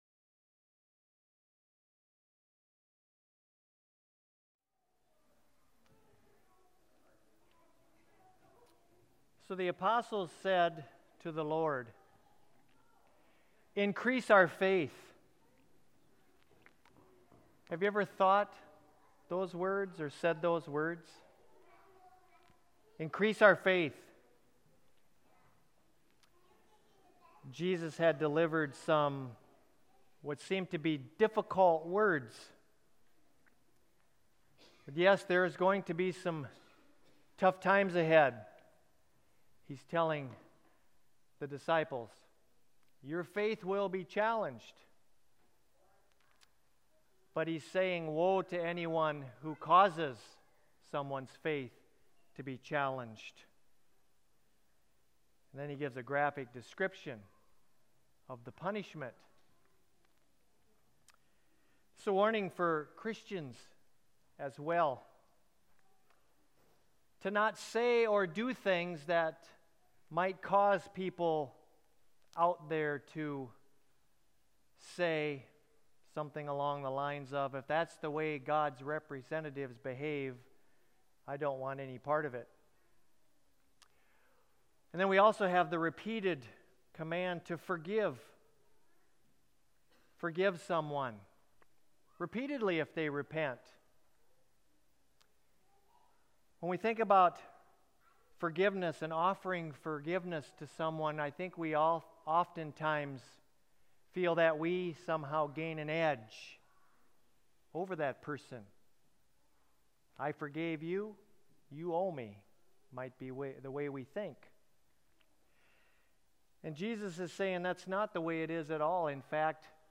church-sermon10.6.19.m4a